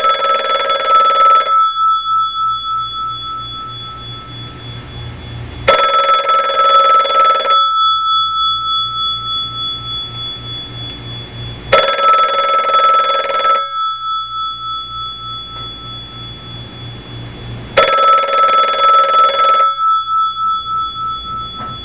Klingelton Telefon 90er Klassischer Klingelton Ring Ring